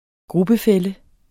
Udtale [ ˈgʁubəˌfεlə ]